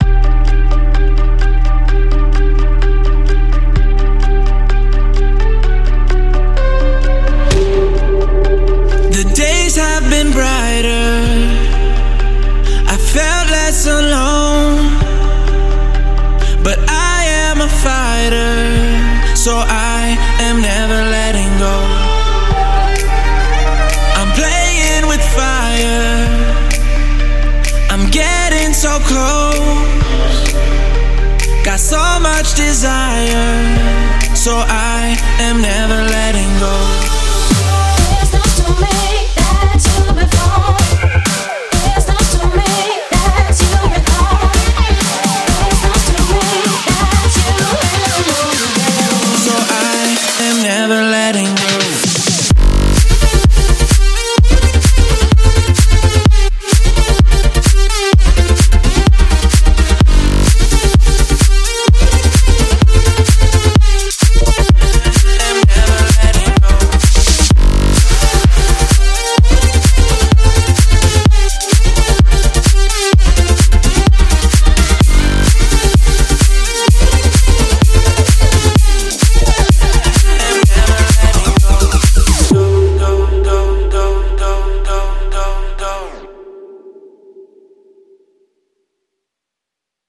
BPM128
MP3 QualityMusic Cut